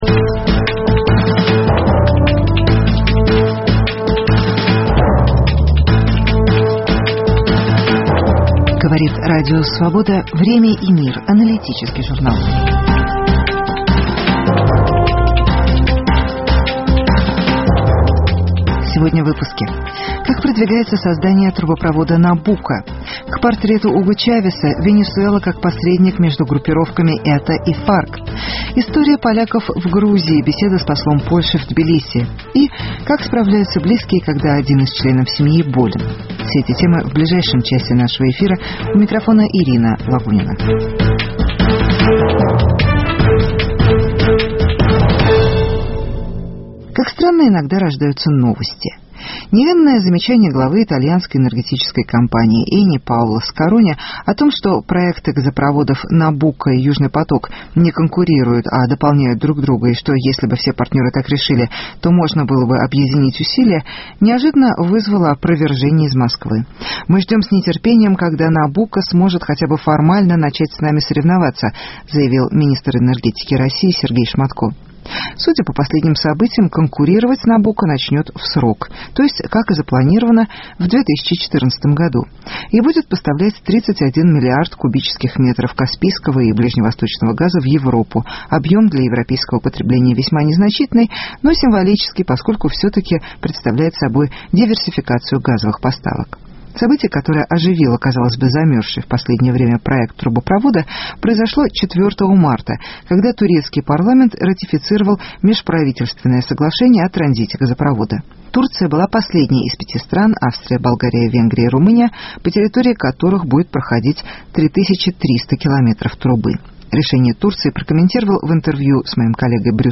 История поляков в Грузии, интервью с послом Польши.